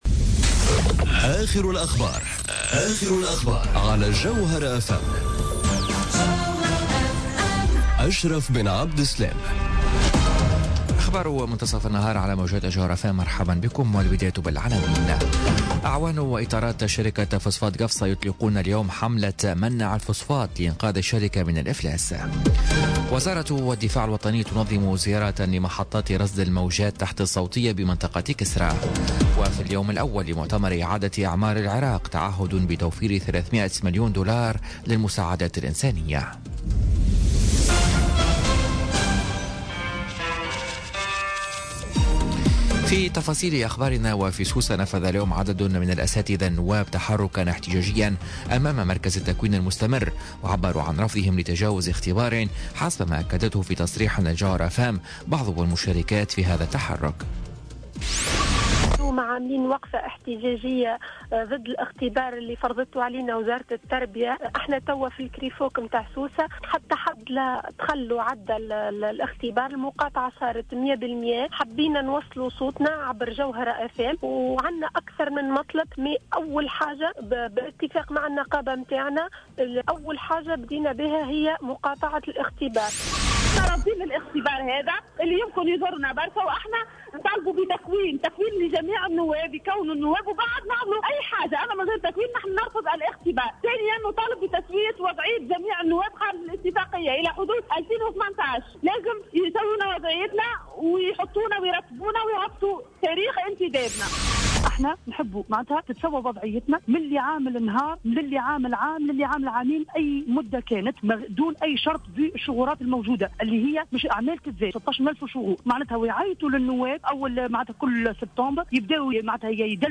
نشرة أخبار منتصف النهار ليوم الإثنين 12 فيفري 2018